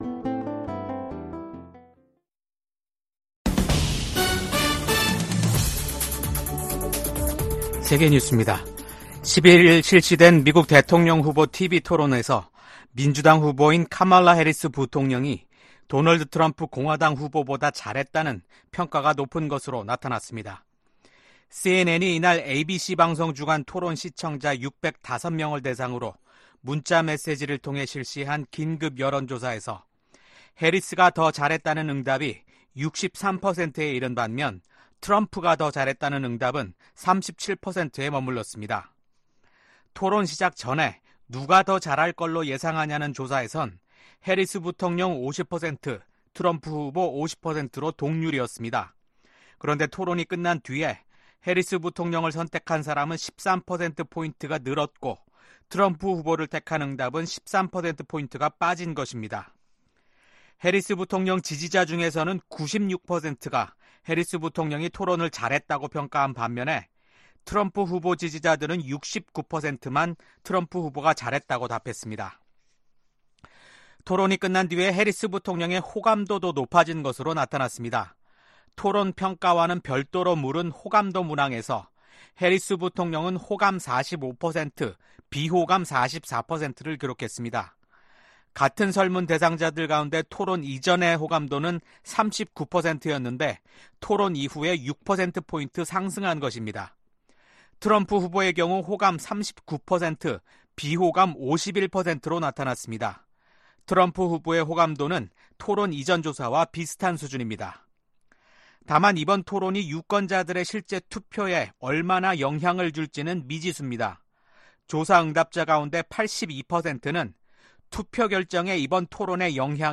VOA 한국어 아침 뉴스 프로그램 '워싱턴 뉴스 광장' 2024년 9월 12일 방송입니다. 오는 11월 대선에서 맞붙는 카멀라 해리스 부통령과 도널드 트럼프 전 대통령이 TV 토론회에 참석해 치열한 공방을 벌였습니다. 미국, 한국, 일본 간 협력 강화를 독려하는 결의안이 미 하원 본회의를 통과했습니다. 서울에선 68개 국가와 국제기구 고위 인사들이 참석한 가운데 다자 안보회의체인 서울안보대화가 열렸습니다.